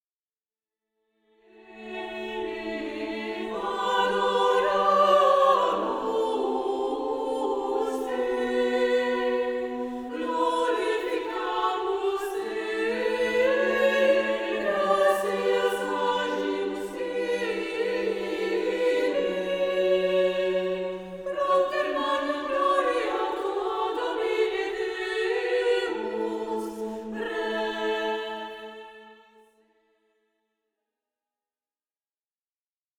Plain-chant et polyphonies du 14e siècle
Motet